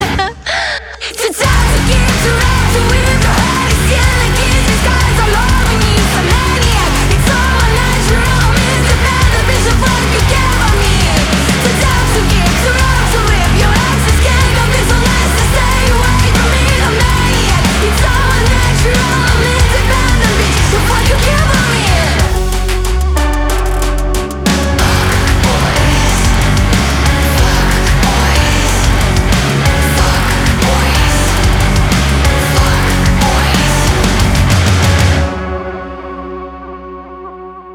Громкие Рингтоны С Басами
Рок Металл Рингтоны